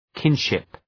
{‘kınʃıp}
kinship.mp3